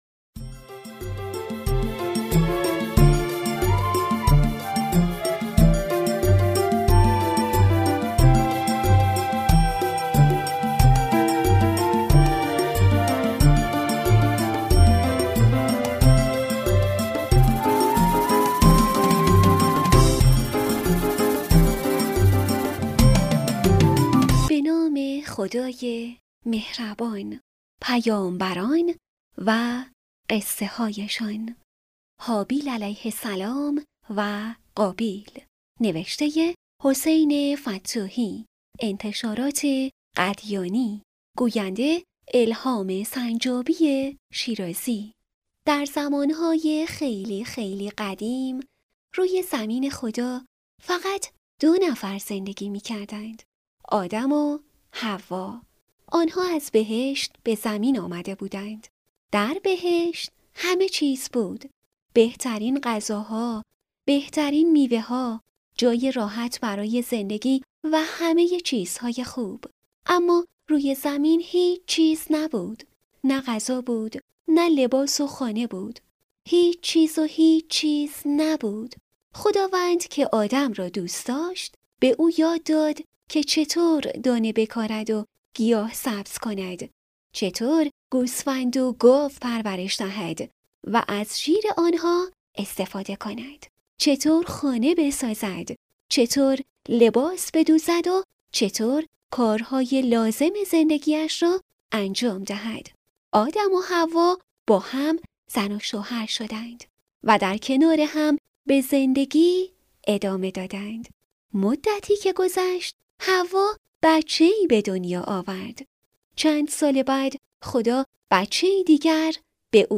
کتاب صوتی «پیامبران و قصه‌هایشان»